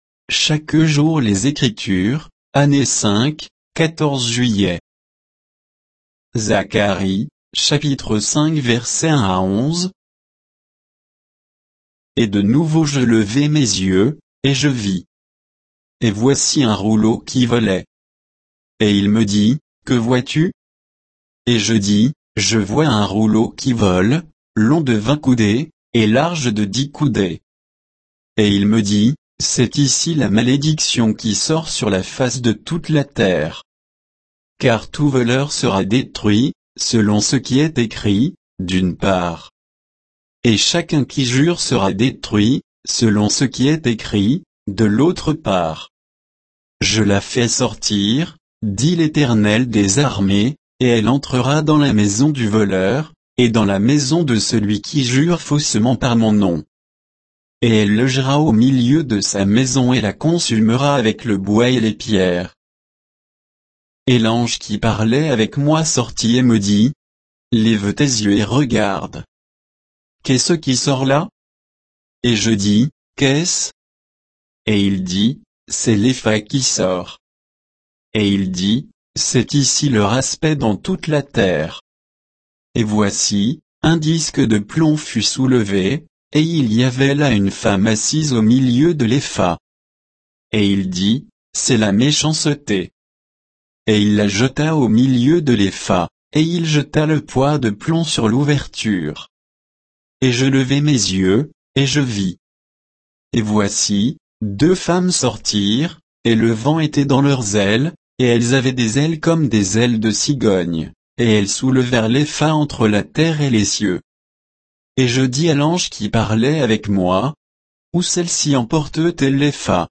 Méditation quoditienne de Chaque jour les Écritures sur Zacharie 5